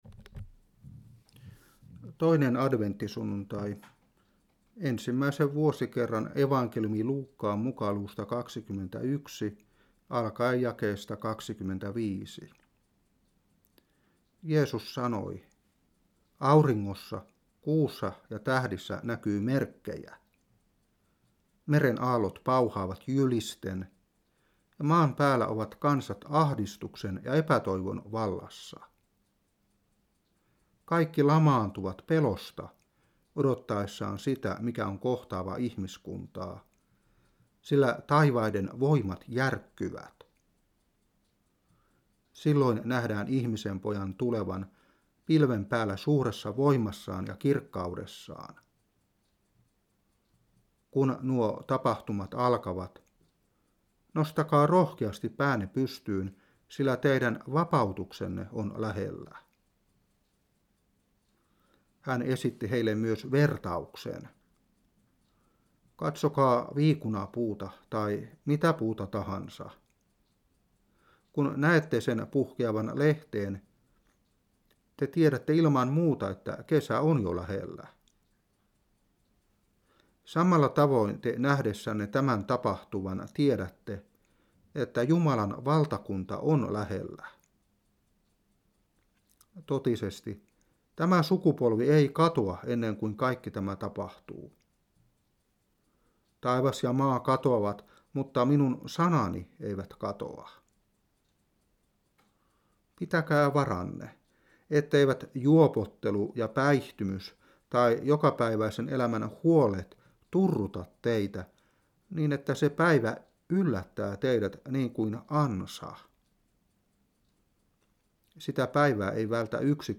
Saarna 2003-12. Luuk.21:25-36.